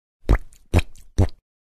Звуки мультяшных шагов
Шаги комичные